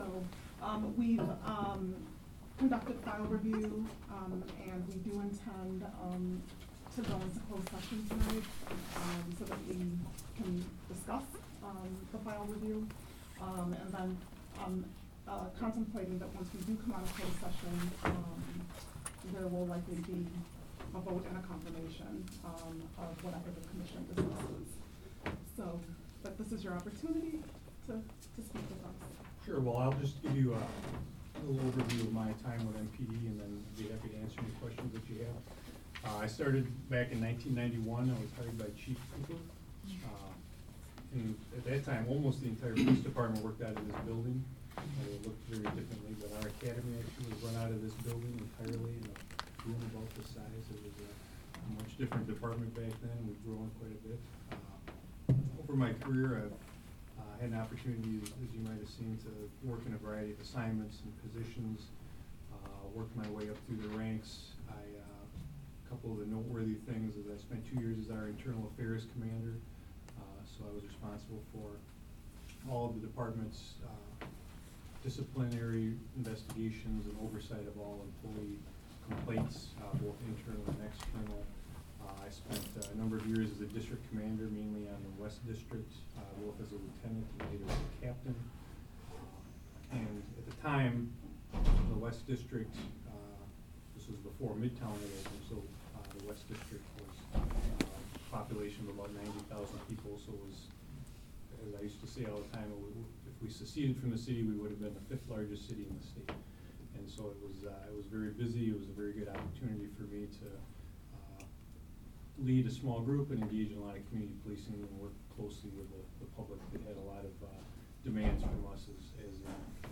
The Police and Fire Commission met last night and made it official that Vic Wahl will be the acting police chief while they go through their process. Here's their statement and audio of Wahl's testimony. He will not run for the permanent chief position because he doesn't want to move to Madison.